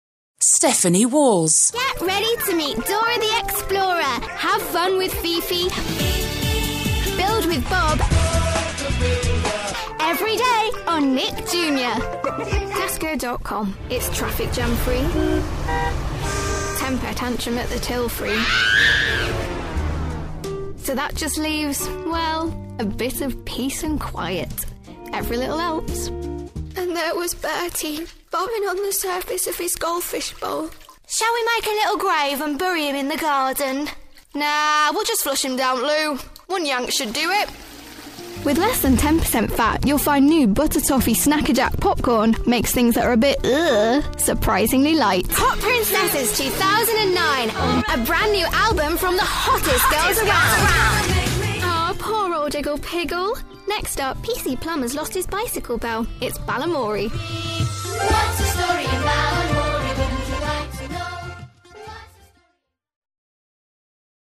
Female / 10s, 20s, child, Teens / English / Northern
Showreel